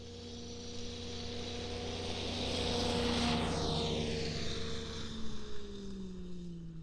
Compression Ignition Snowmobile Description Form (PDF)
Compression Ignition Subjective Noise Event Audio File (WAV)